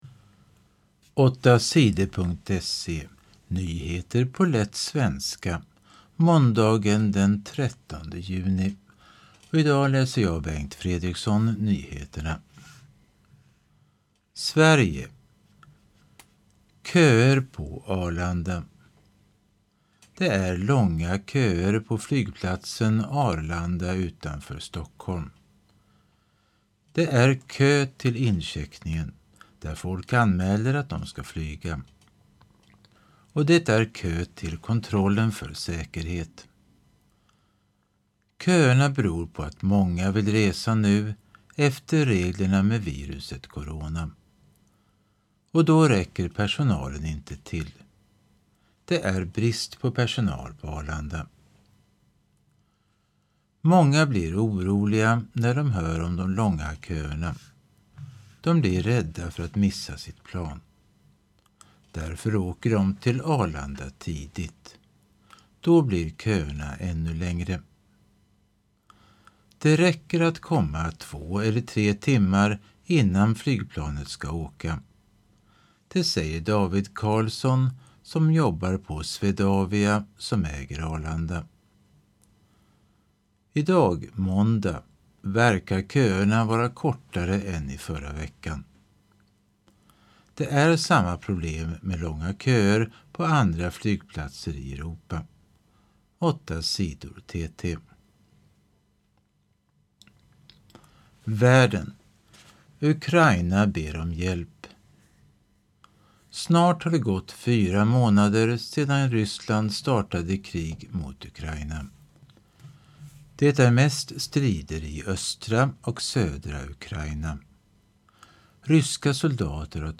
Nyheter på lätt svenska den 13 juni